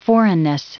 Prononciation du mot foreignness en anglais (fichier audio)
Prononciation du mot : foreignness